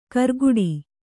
♪ karguḍi